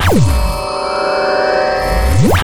some more puzzle sounds
solved.wav